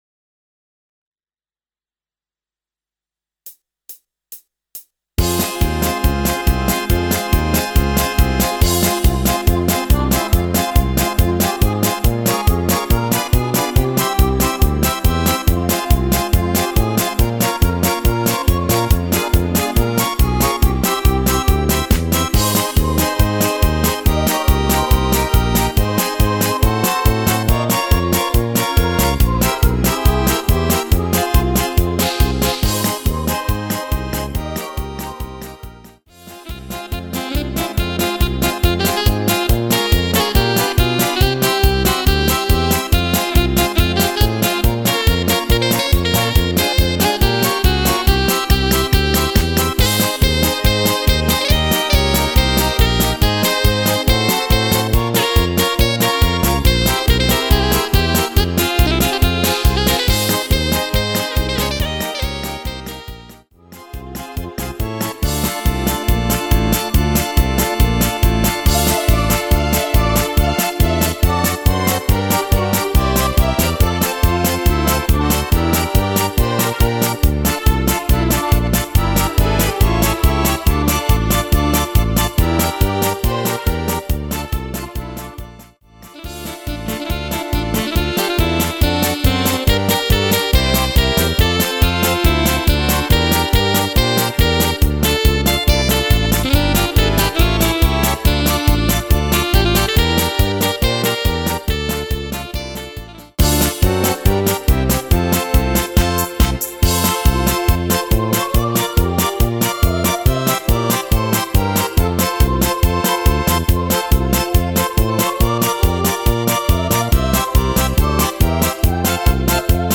Rubrika: Pop, rock, beat
- směs